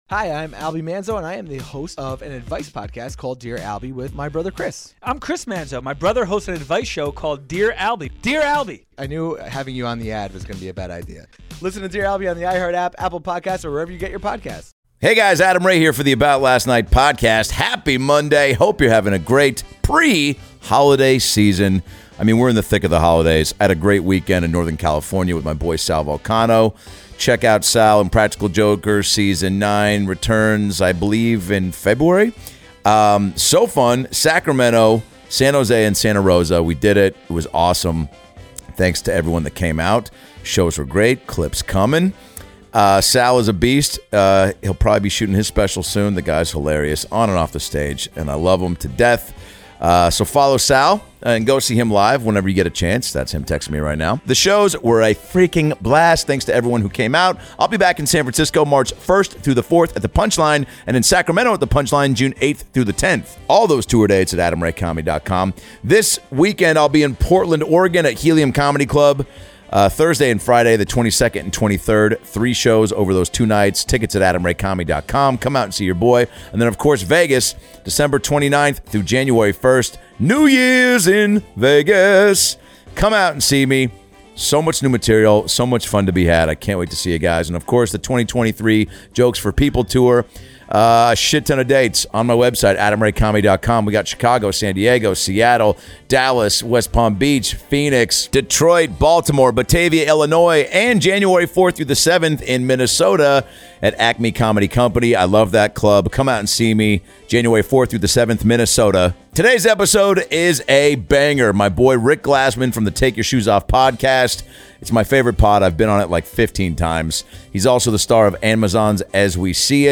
From his favorite holiday memories, to MC HAMMER, to how to impress a girl on a first date, NSYNC vs Backstreet, his favorite comedy style, the TAKE YOUR SHOES OFF podcast, Santa, the joy of being a kid, and much much more! Enjoy two best buds riffing like idiots for over an hour.